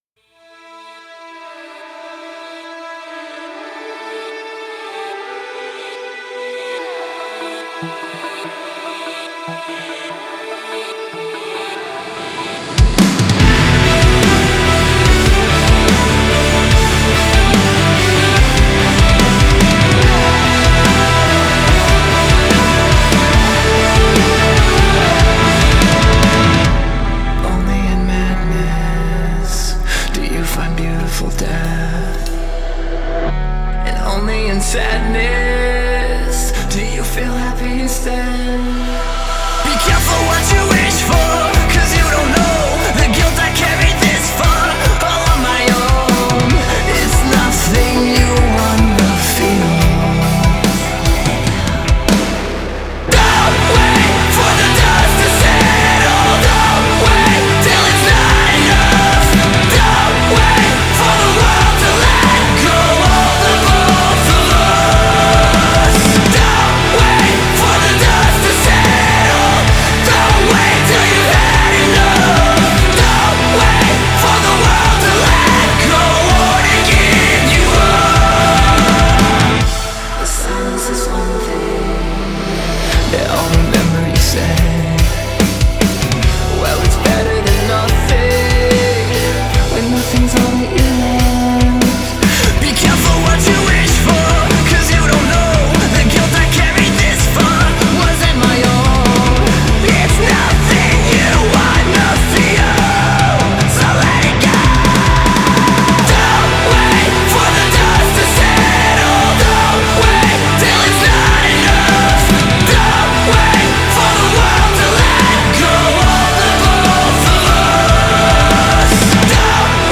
متال Metal